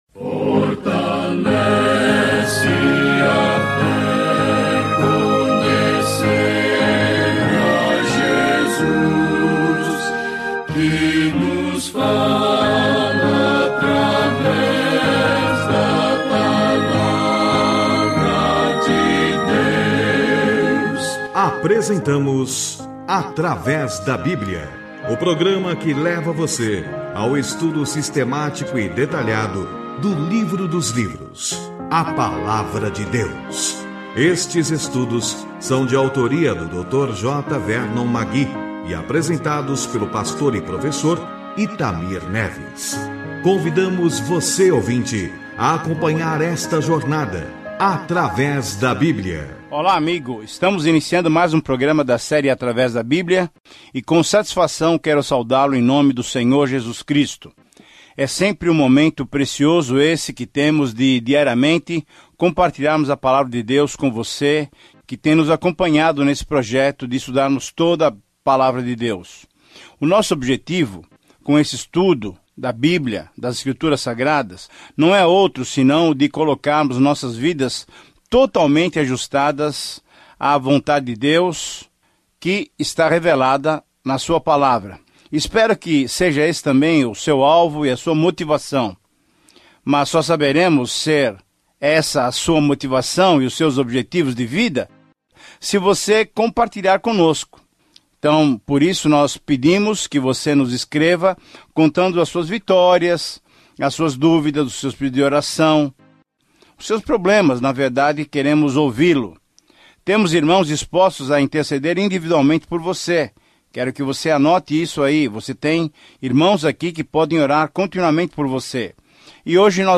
Viaje diariamente por Gênesis enquanto ouve o estudo em áudio e lê versículos selecionados da palavra de Deus no livro de Gênesis.